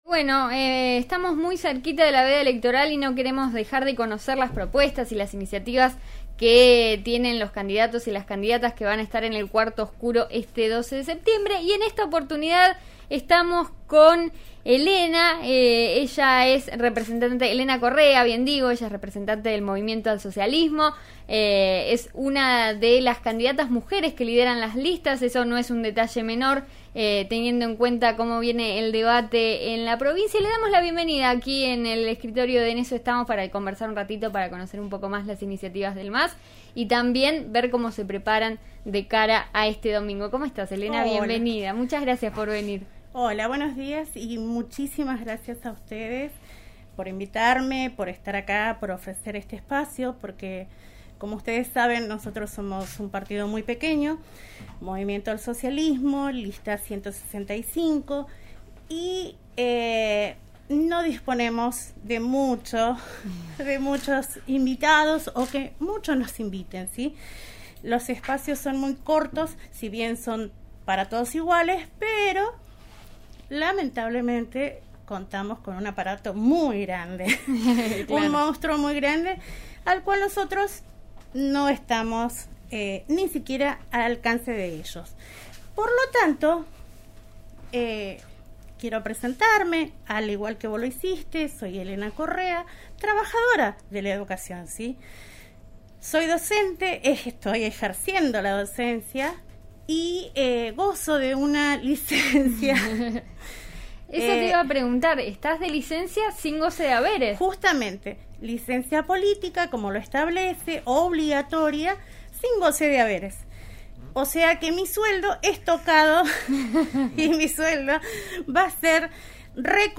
En la semana previa a las PASO, En eso estamos de RN RADIO 89.3 invitó al estudio a algunos de los precandidatos a diputados y diputadas por la provincia de Río Negro, quienes hablaron sobre sus propuestas. Además, se prestaron a un ping pong de preguntas, en las que respondieron sobre algunos temas de importancia de actualidad.